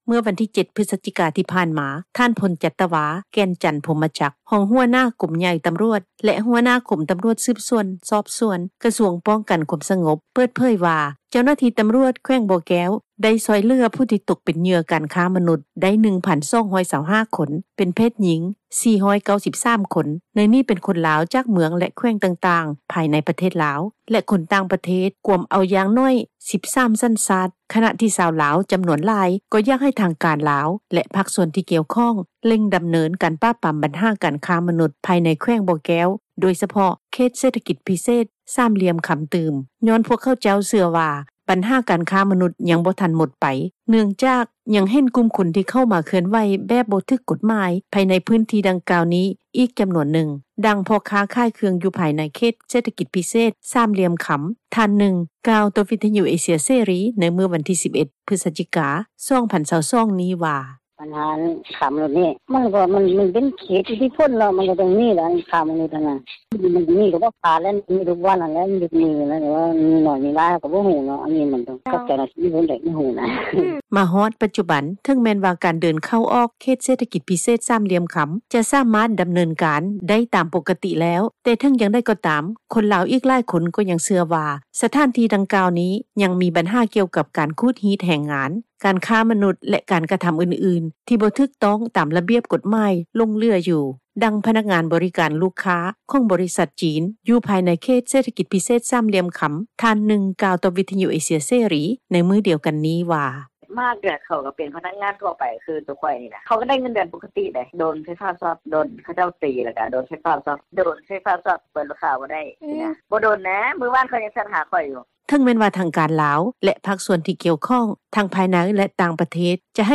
ດັ່ງ ພໍ່ຄ້າຂາຍເຄື່ອງ ຢູ່ພາຍໃນເຂດເສຖກິຈ ພິເສດ ສາມຫ່ລຽມຄໍາ ທ່ານນຶ່ງ ກ່າວຕໍ່ວິທະຍຸ ເອເຊັຽ ເສຣີໃນມື້ວັນທີ 11 ພືສຈິກາ 2022 ນີ້ວ່າ:
ດັ່ງເຈົ້າໜ້າທີ່ ທີ່ເຮັດວຽກ ດ້ານການສະກັດກັ້ນ ການຄ້າມະນຸສ ຢູ່ແຂວງທາງພາກເໜືອ ຂອງລາວ ນາງນຶ່ງກ່າວວ່າ:
ດັ່ງ ເຈົ້າໜ້າທີ່ ທີ່ກ່ຽວຂ້ອງ ຜູ້ທີ່ບໍ່ປະສົງອອກຊື່ ແລະຕໍາແຫນ່ງ ທ່ານນຶ່ງ ກ່າວວ່າ: